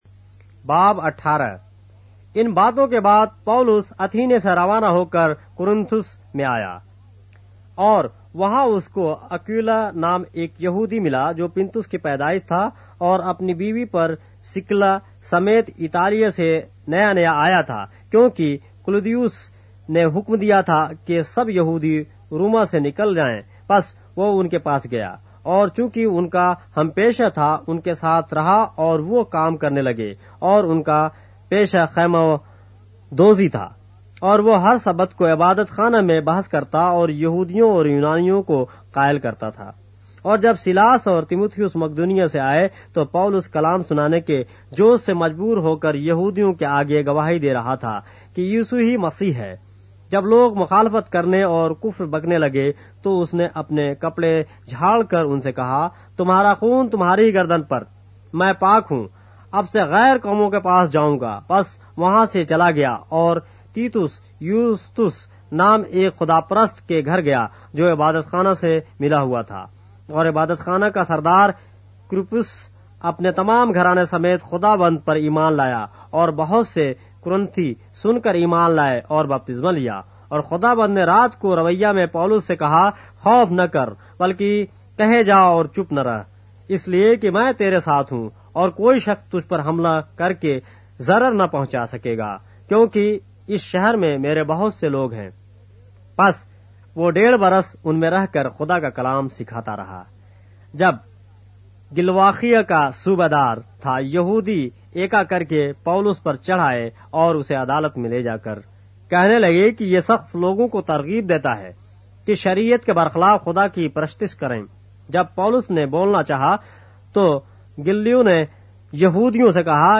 اردو بائبل کے باب - آڈیو روایت کے ساتھ - Acts, chapter 18 of the Holy Bible in Urdu